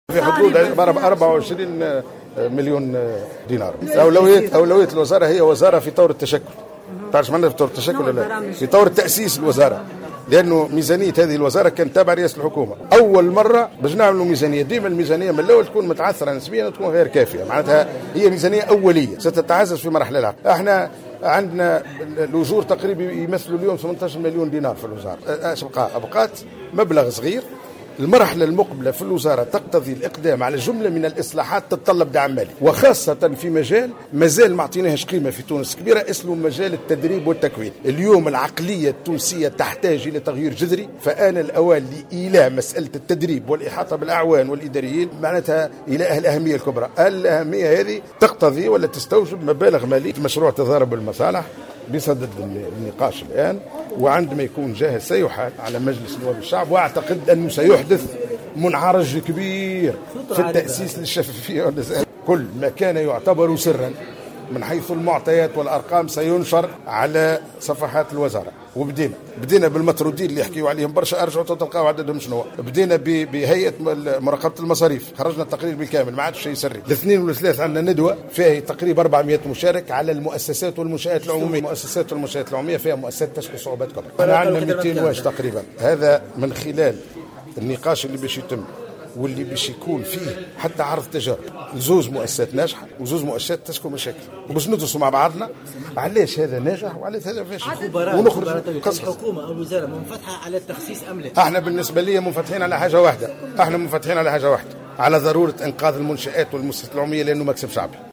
وبيّن في تصريح للجوهرة أف أم، بعد الاستماع اليه اليوم الأربعاء، في لجنة الحقوق والحريات حول مشروع قانون حماية المبلغين عن الفساد، أن جانبا هاما من الميزانية المخصصة لوزارة الوظيفة العمومية والحوكمة سيُوجه للأجور في حين أن المرحلة المقبلة تستدعي الخوض في جملة من الإصلاحات التي تتطلب دعما ماليا كافيا.